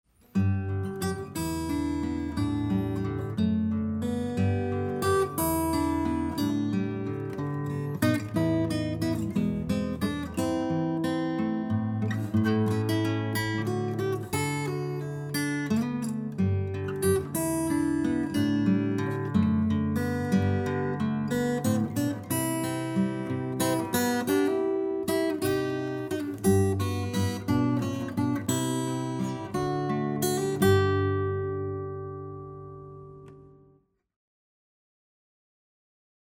beautifully simple acoustic rendition